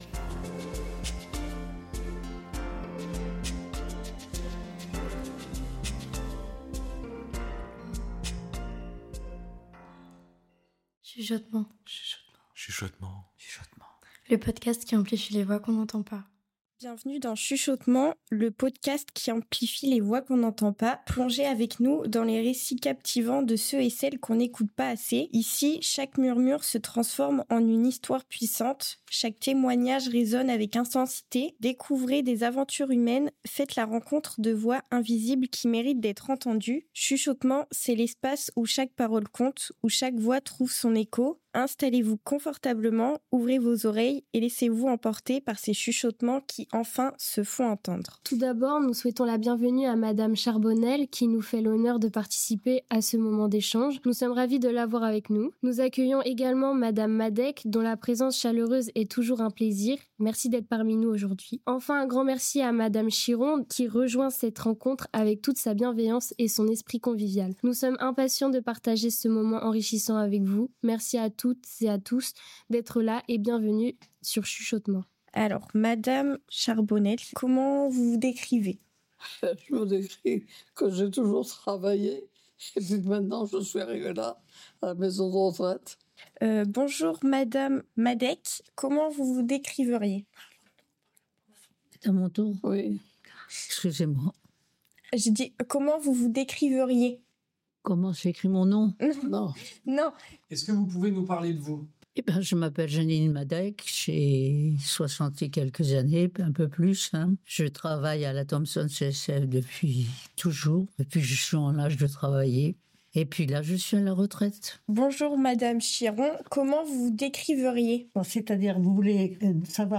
Cet épisode est le premier d'une série réalisée avec les résident·es de l'EHPAD Vivre ensemble à La Jumellière.
Dans cet épisode, nous plongeons dans leurs souvenirs de jeunesse. Ces voix sages nous offrent aussi leur regard sur la jeunesse d'aujourd'hui.